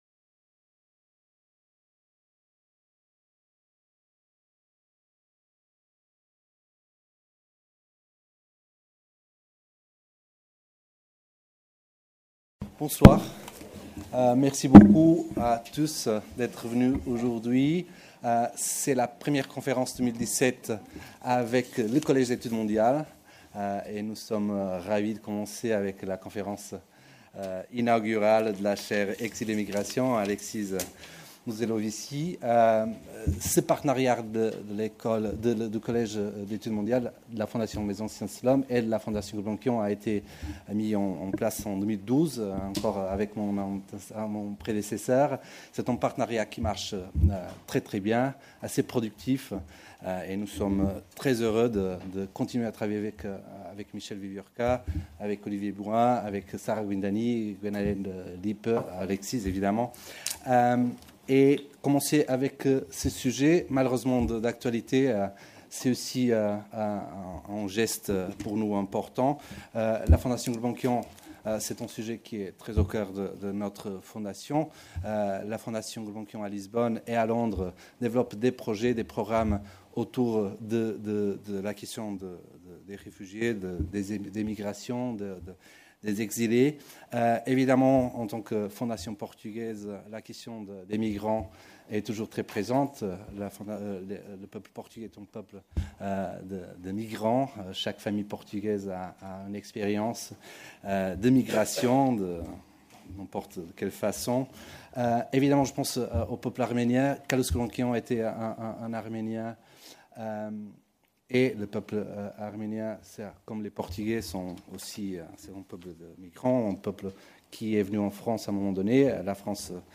le 14 février à la Fondation Gulbenkian, en présence de Michel Wieviorka, fondateur du Collège d'études mondiales et président de la Fondation maison des sciences de l'homme. De par sa nature et son ampleur, l’exil de masse contemporain ne constitue pas un chapitre de plus dans l’histoire des migrations en Europe. Le phénomène, par conséquent, appelle des approches novatrices afin d’en saisir les paramètres et redéfinir une politique de l’hospitalité pour notre temps.